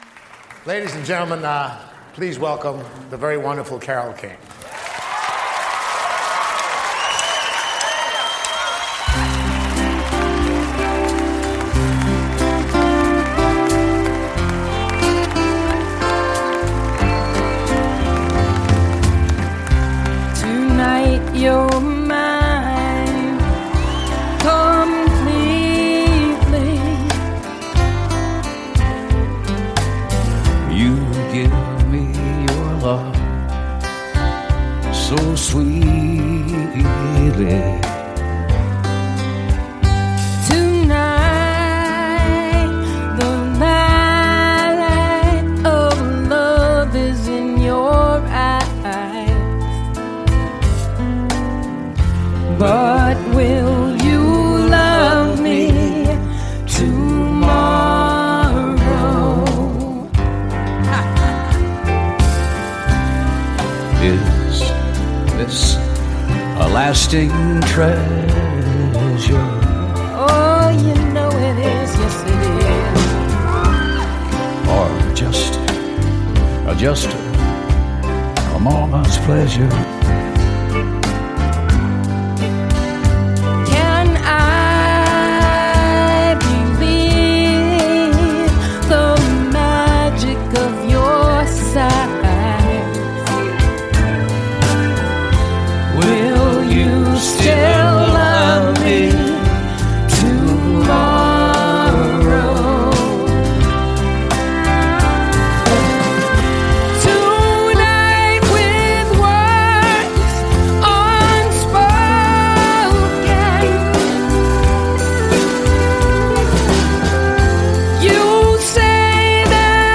Live 2004_Wiltern Theatre, Los Angeles